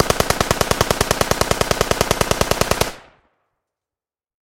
Звуки автомата Калашникова
Звук продолжительной стрельбы из Калашникова